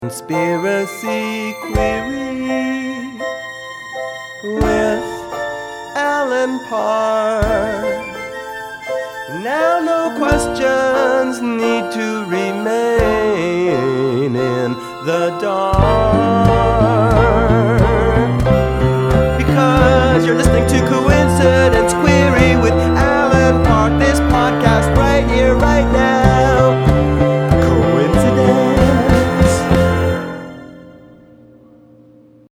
I thought the theme song could be more distinct; I offered to make a new one.